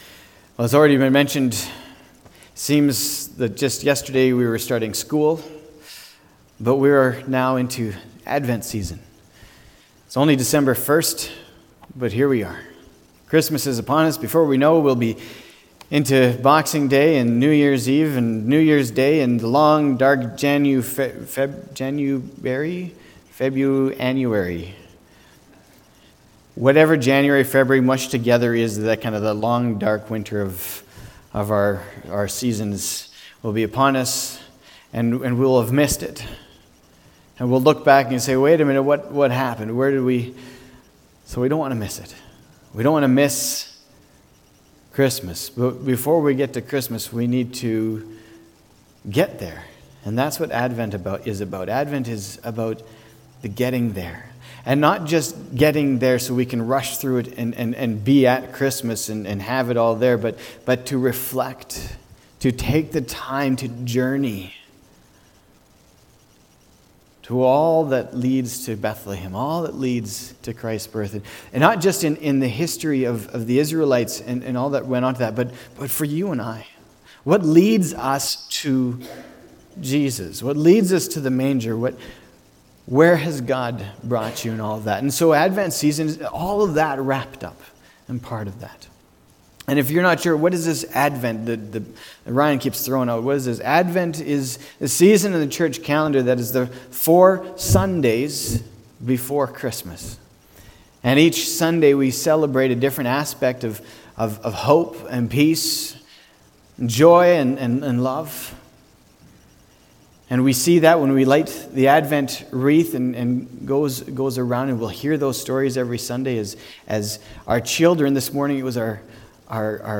december-1-2019-sermon.mp3